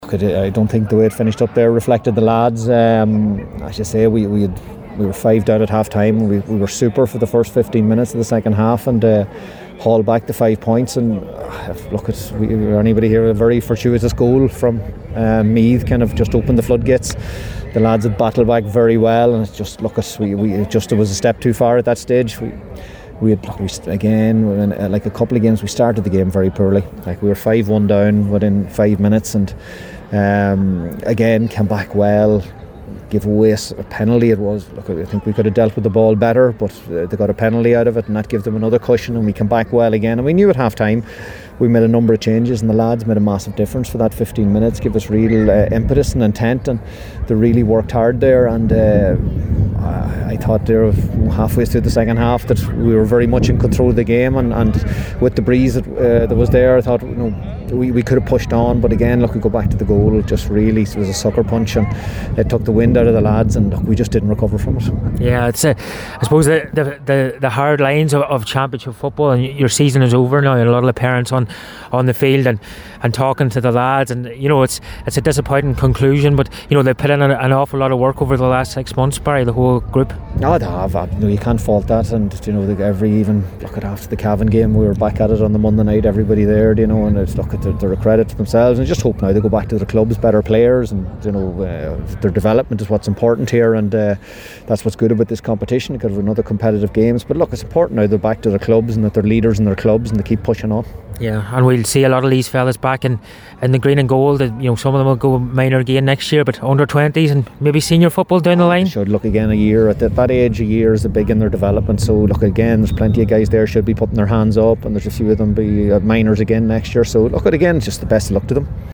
after the game…